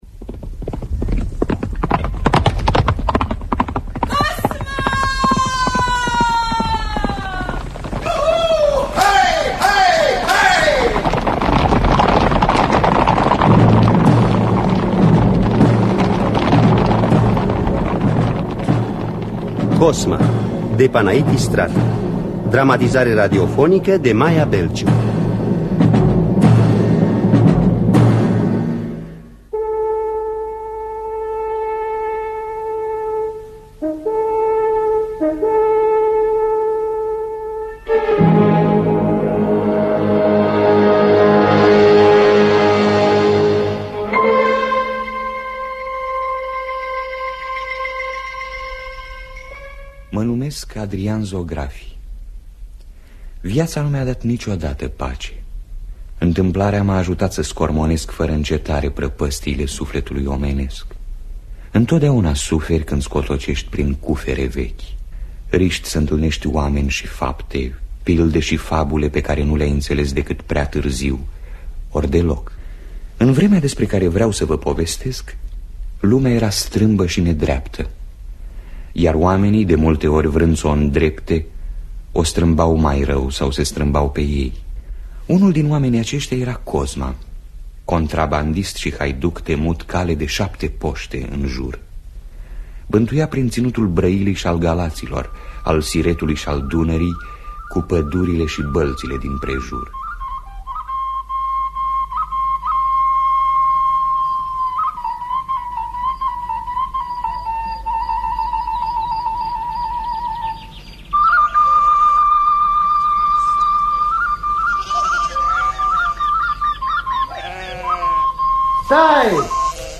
Dramatizarea radiofonică de Maia Belciu.